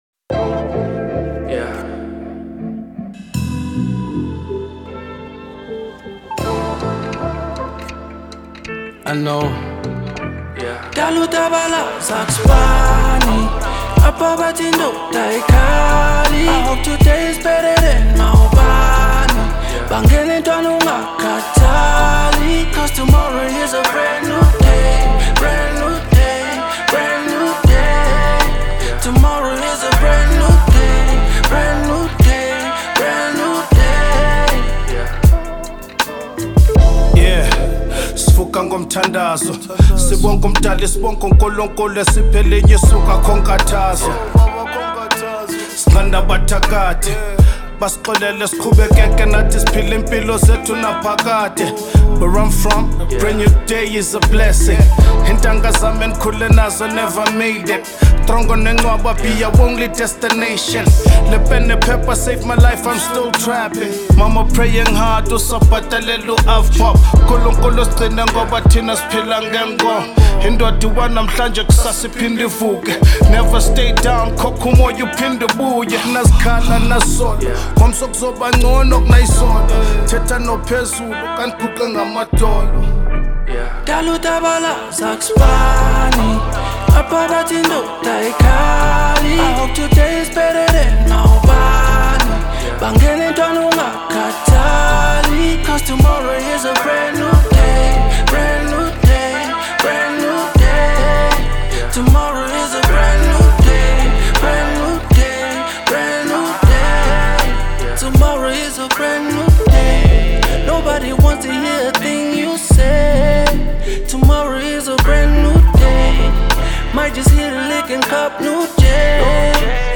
South African hip hop